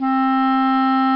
Clarinet Soft Sound Effect
Download a high-quality clarinet soft sound effect.
clarinet-soft.mp3